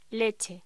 Locución: Leche
voz